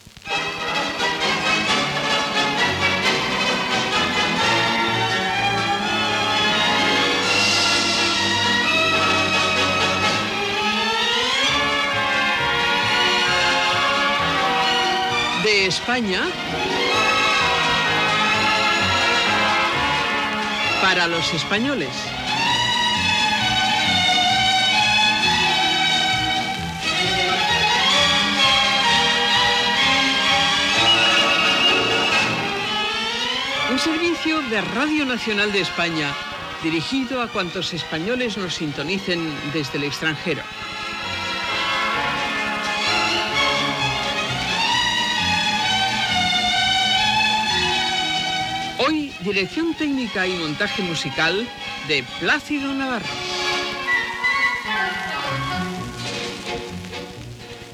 Sintonia i presentació